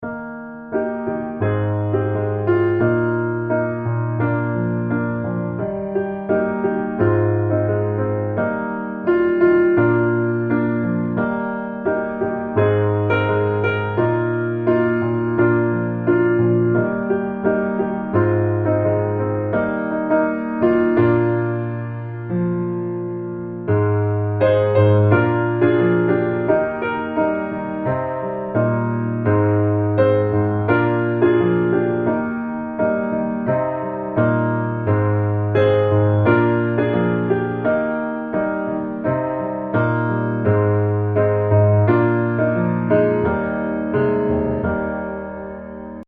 降E大調